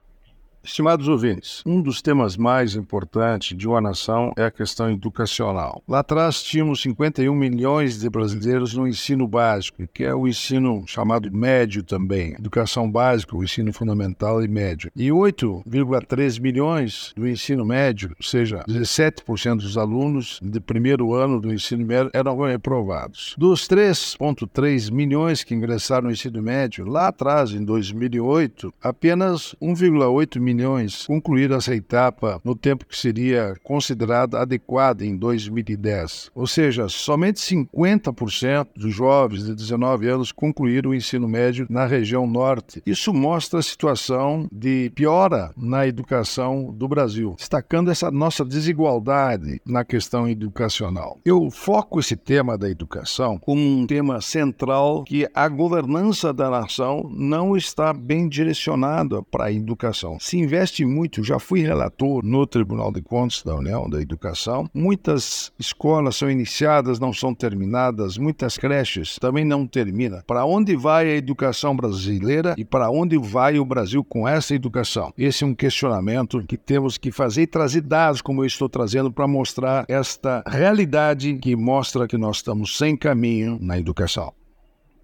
É o assunto do comentário do ministro do Tribunal de Contas da União, Augusto Nardes, desta quarta-feira (17/07/24), especialmente para OgazeteirO.